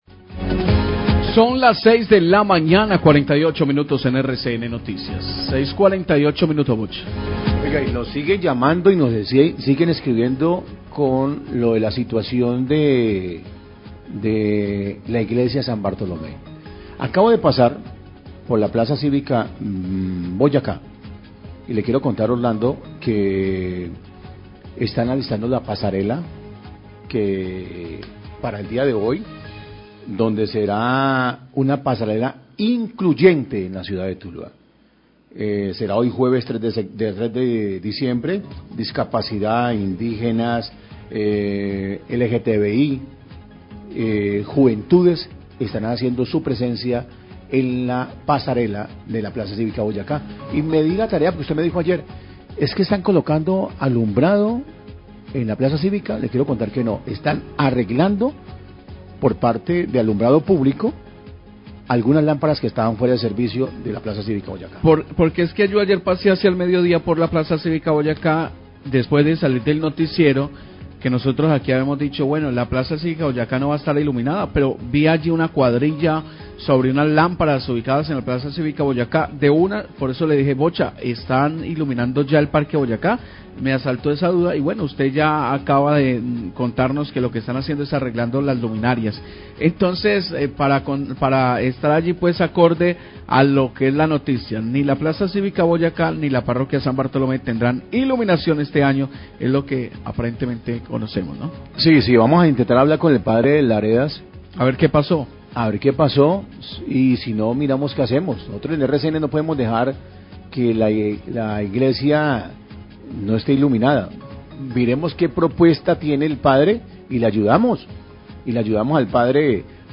Radio
Periodistas comentan sobre trabajos en las luminarias del alumbrado público de la Plaza Cívica Boyacá y que confundieron con trabajos para el alumbrado navideño. Afirman que no habrá este tipo de alumbrado ni en la plaza cívica ni en la Iglesia de San Bartolomé.